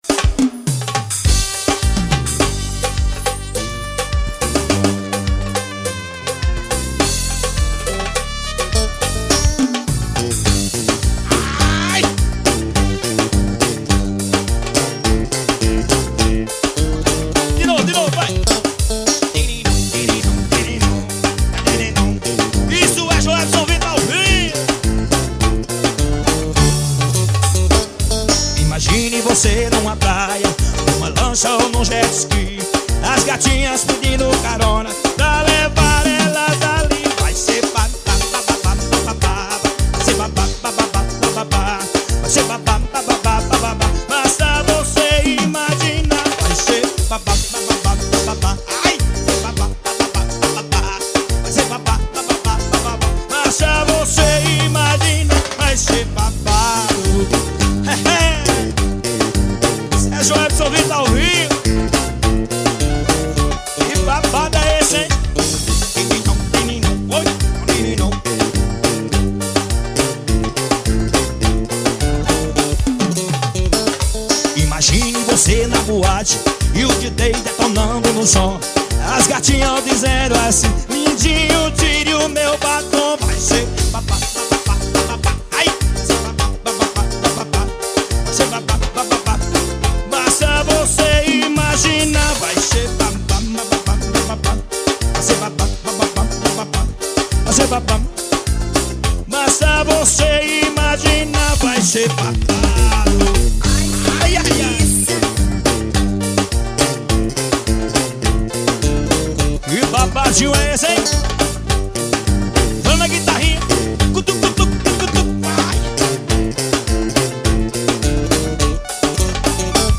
forrozão.